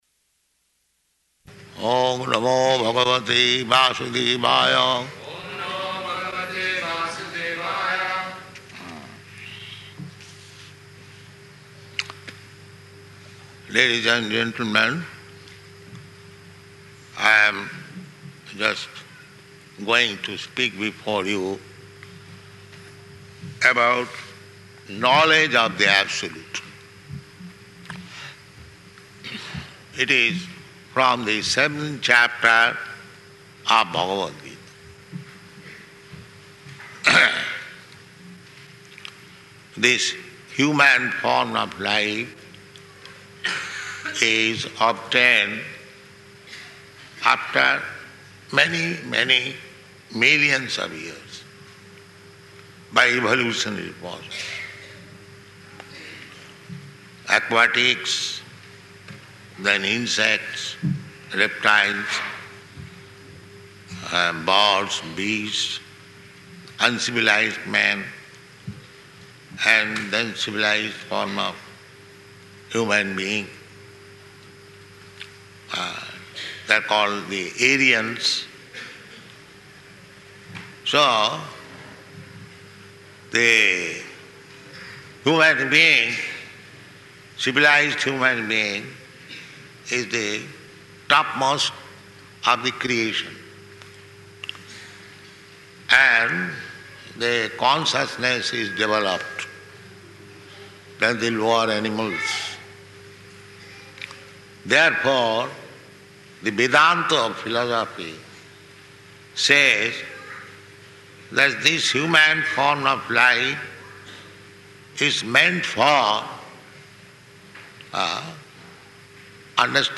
Bhagavad-gītā 7.1 --:-- --:-- Type: Bhagavad-gita Dated: May 24th 1975 Location: Fiji Audio file: 750524BG.FIJ.mp3 Prabhupāda: Oṁ namo bhagavate vāsudevāya. [devotees chant responsively] Hmm. Ladies and gentlemen, I am just going to speak before you about knowledge of the Absolute.